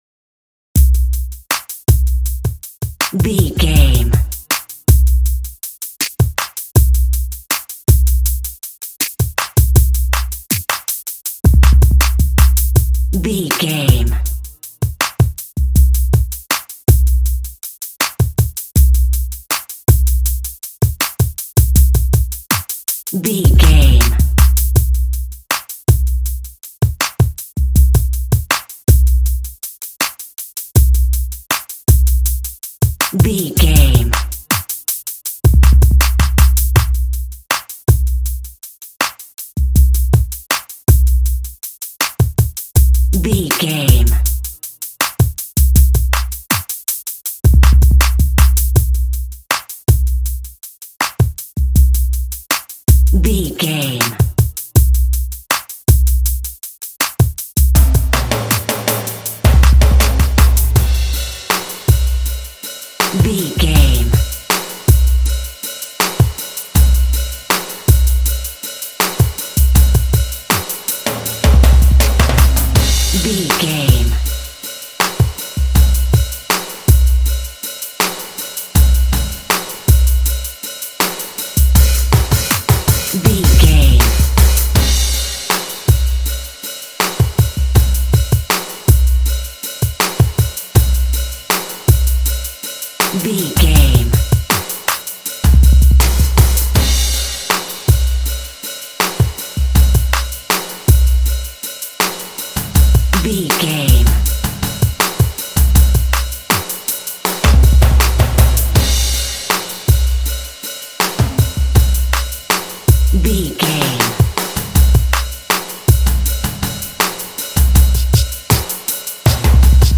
Fast paced
Aeolian/Minor
F#
ominous
tension